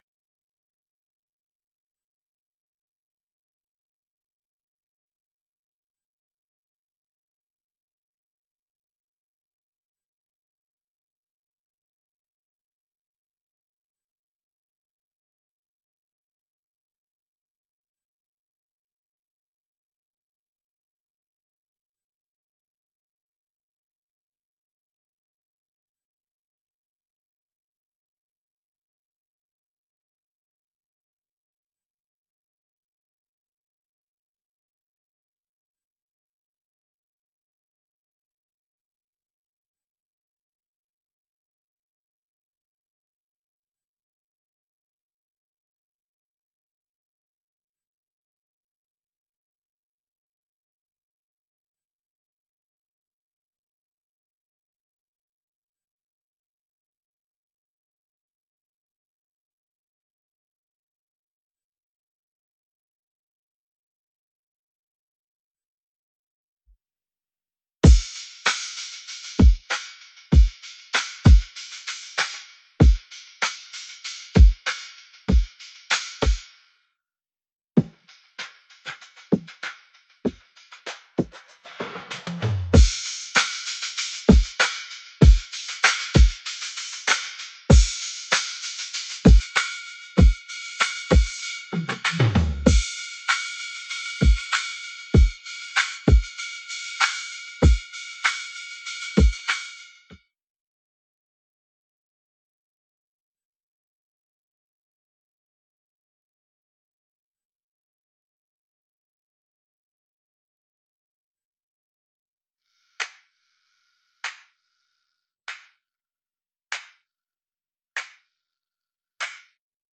Instruments